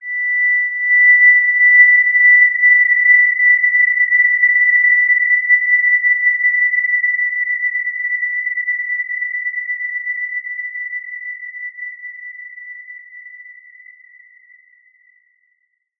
Basic-Tone-B6-mf.wav